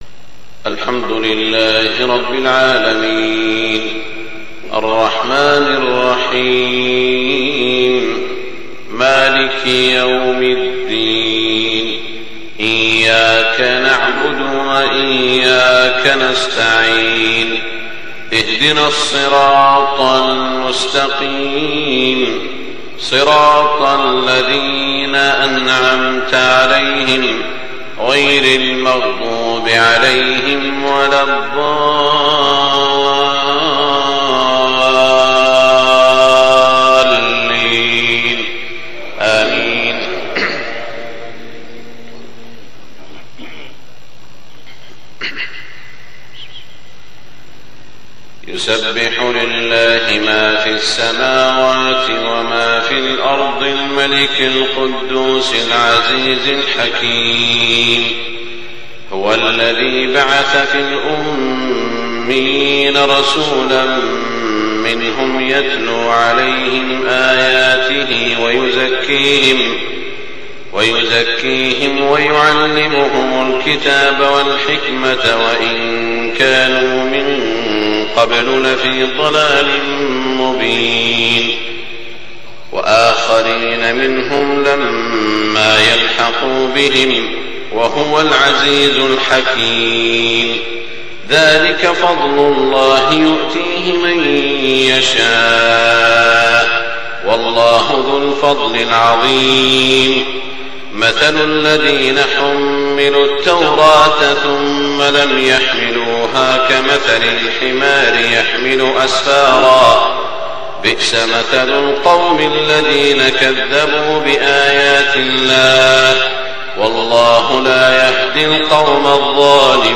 صلاة الفجر 2-1425 سورتي الجمعة البلد > 1425 🕋 > الفروض - تلاوات الحرمين